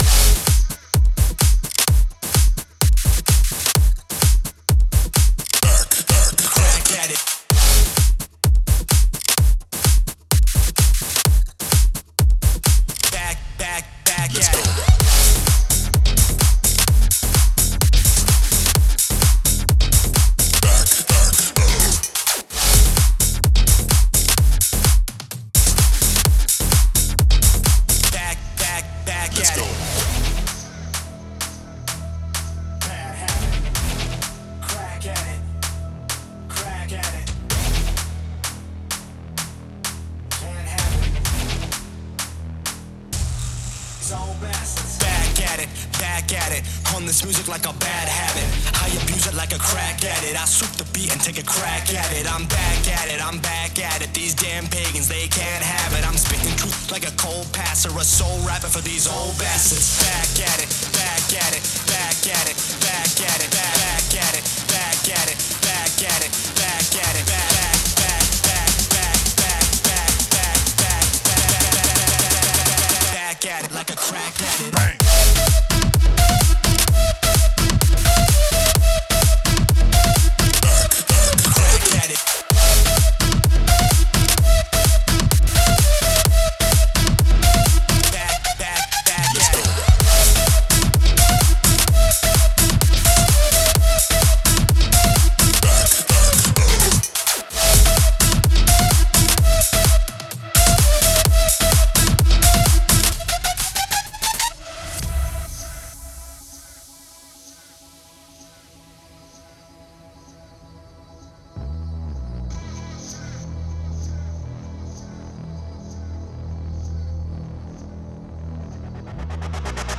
Type: Serum Samples